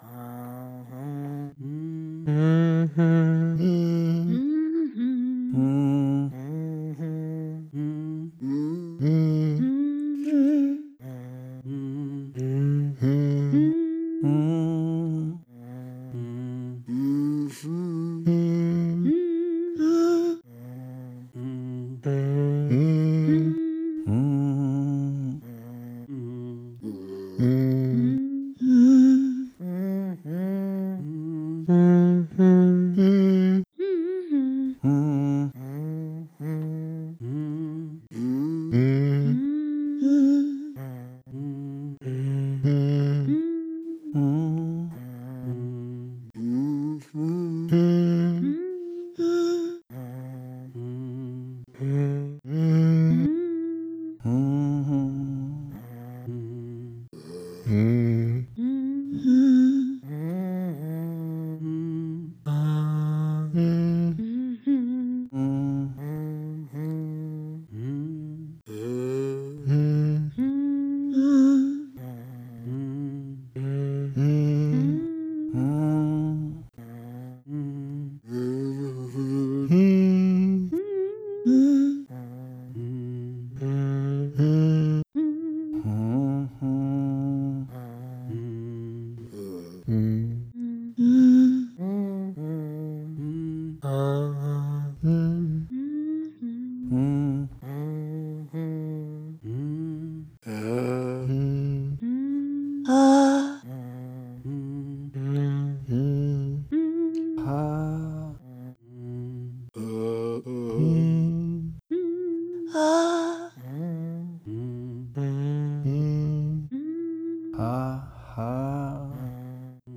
The words were sung from one person to another in a circular formation. The three verses were repeated with each cycle becoming less decipherable than the cycle before until the sound became a moving wave of vocal undulations and breath and the song became completely unrecognizable. The first three cycles are hummed and then chanted for another two cycles before becoming mainly breath.
We went through the verses, one person at a time in the indicated pattern, each person used their phone to record their own voice. The audio was bounced as one track for the purpose of providing a sound clip, but as a performance piece, the words move from one person to another transforming the song into a circular movement of sound.